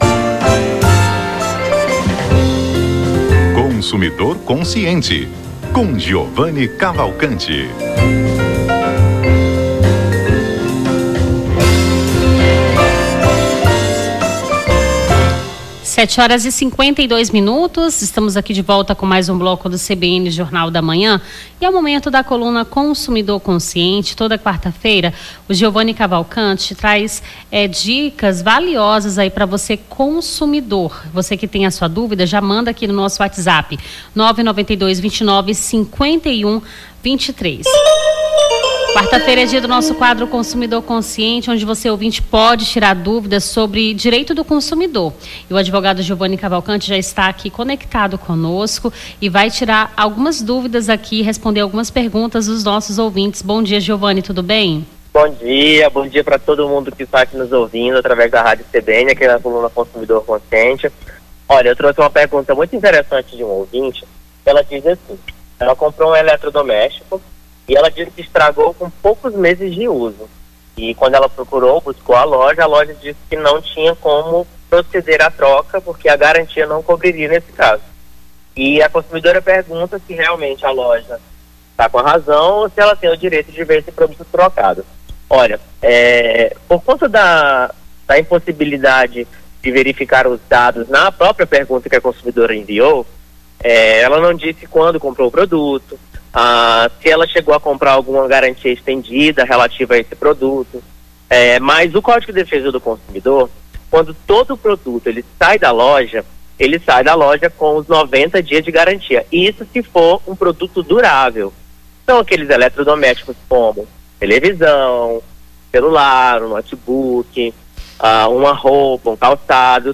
Consumidor Consciente: Advogado tira dúvidas dos ouvintes sobre o direito do consumidor